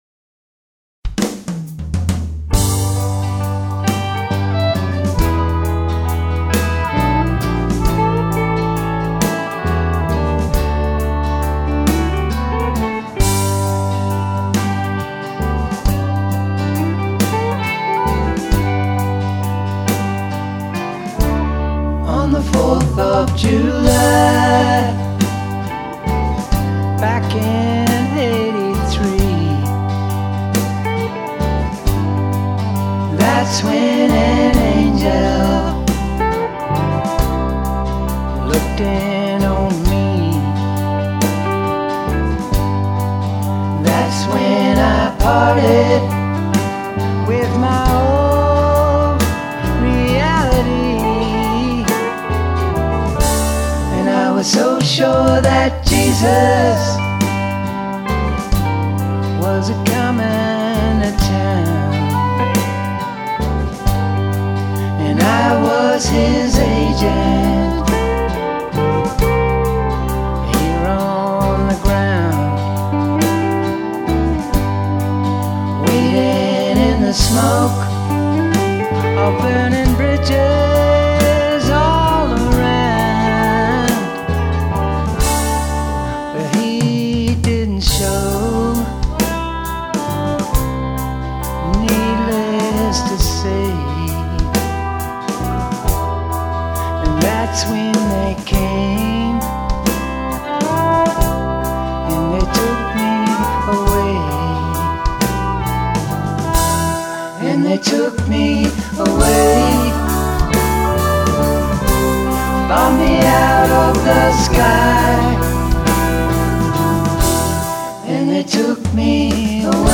violin
I built a new drum track and mixed it again in January 2025.